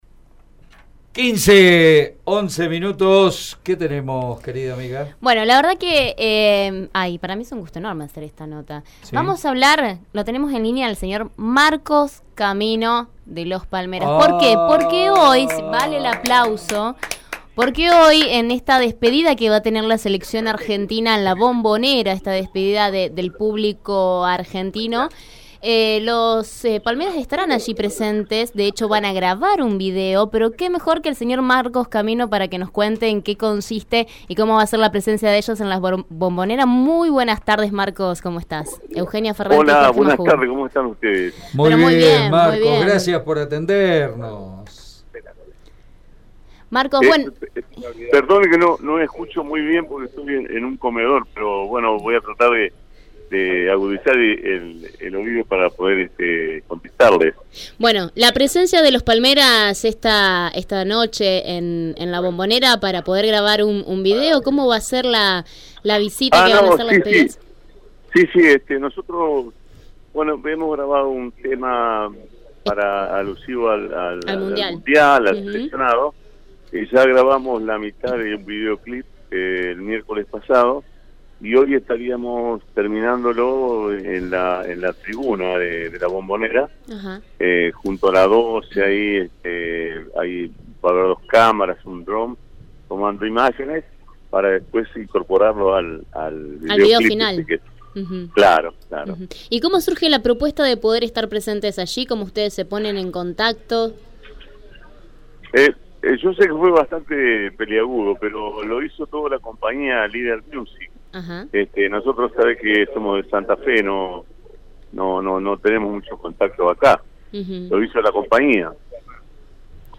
Uno de los músicos de Los Palmeras habló con Radio EME en la previa del partido de Argentina – Haití, donde la banda de cumbia santafesina tocará el Himno y el nuevo tema «Vamos a Volver», el tema de la Selección.